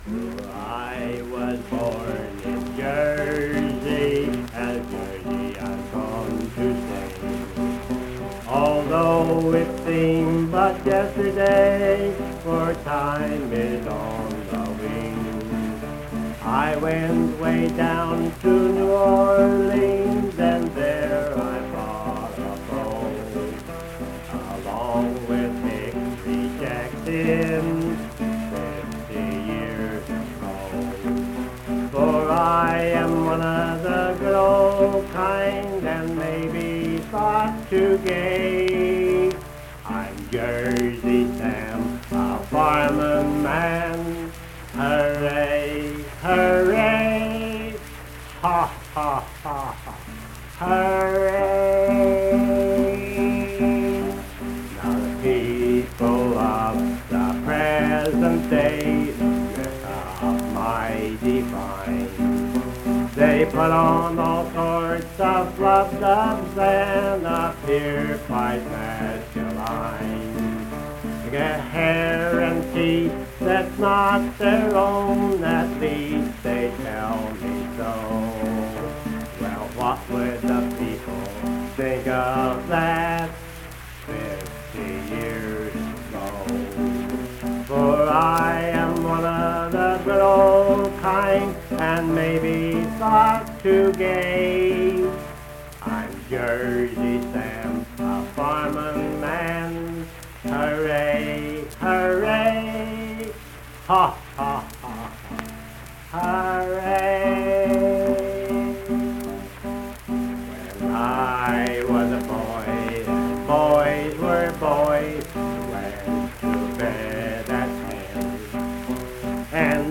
Accompanied vocal and guitar music
Verse-refrain 3d(8). Performed in Hundred, Wetzel County, WV.
Voice (sung), Guitar